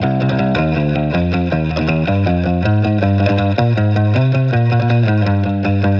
AU_PWCG_160_electric_guitar_loop_age_Cmin.wav